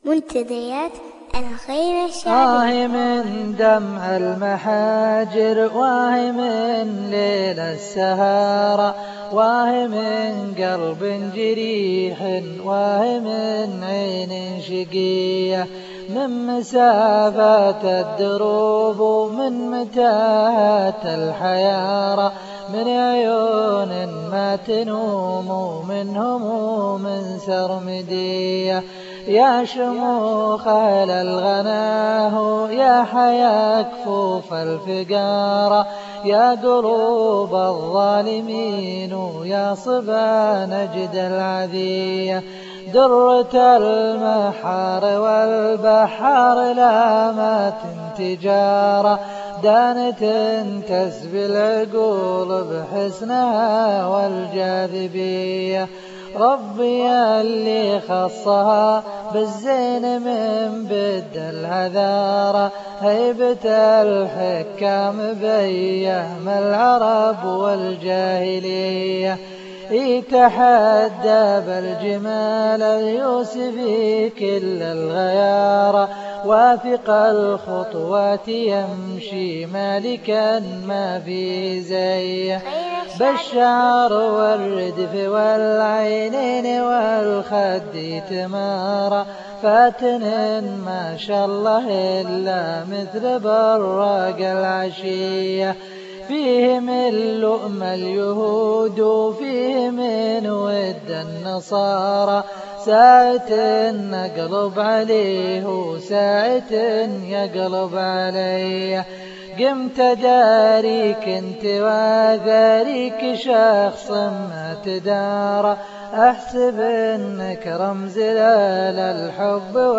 الشيله
الصوت الشجي